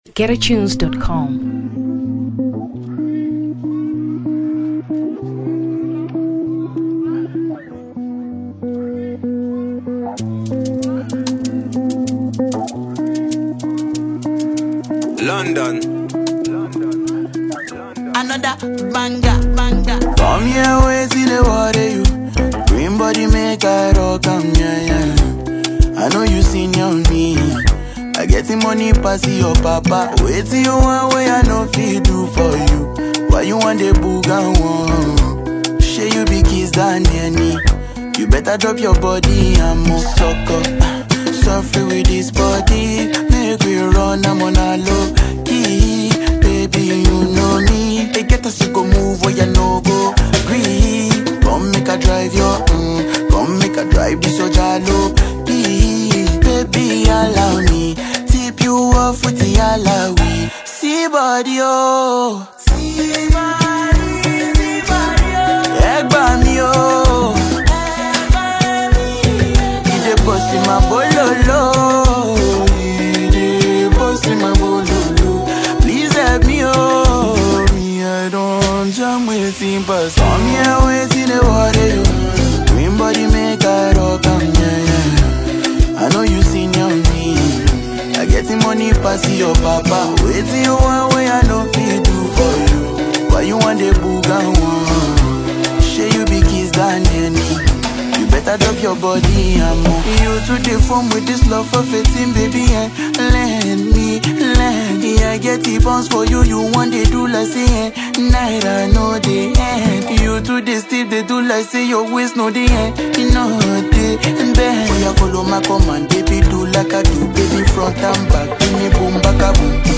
Afrobeat 2023 Nigeria